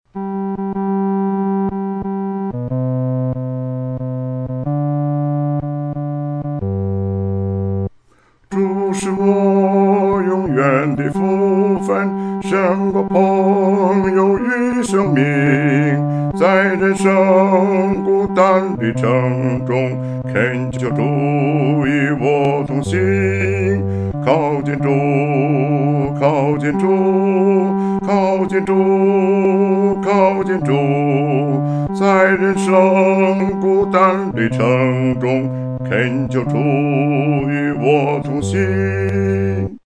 独唱（第四声）
靠近主-独唱（第四声）.mp3